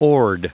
Prononciation du mot oared en anglais (fichier audio)
Prononciation du mot : oared